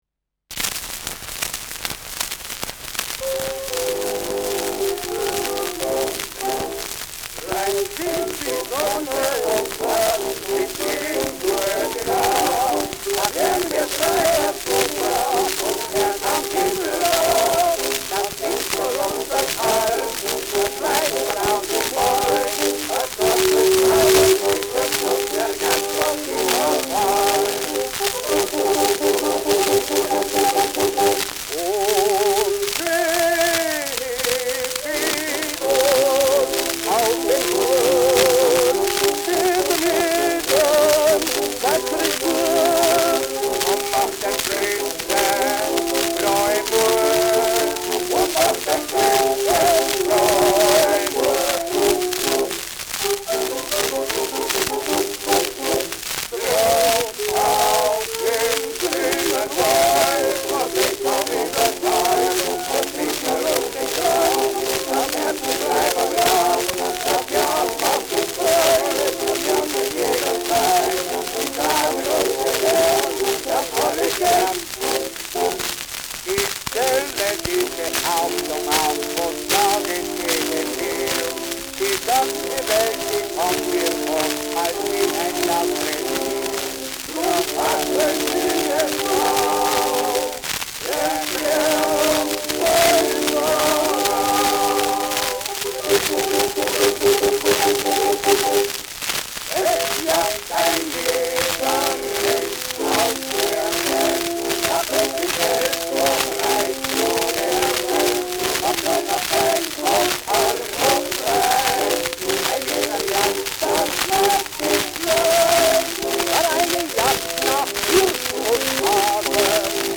Schellackplatte
[München] (Aufnahmeort)
Humoristischer Vortrag* FVS-00003